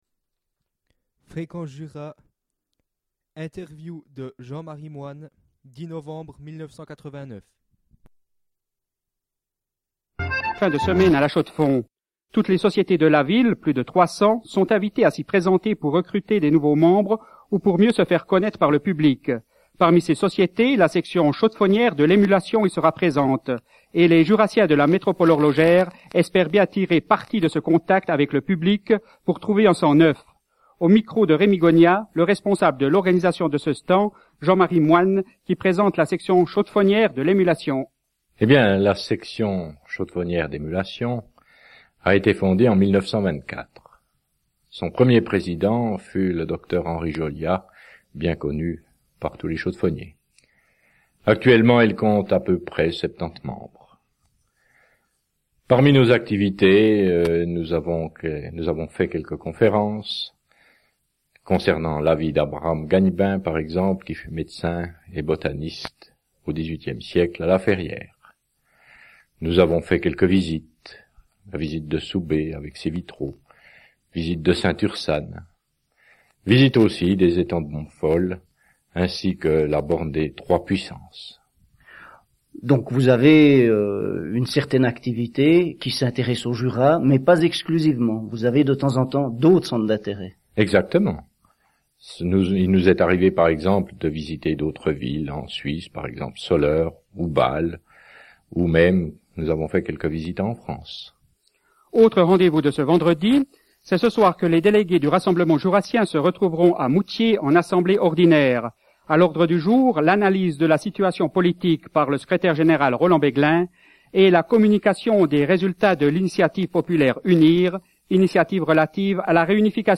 Emission du 10 novembre 1989 sur Fréquence Jura.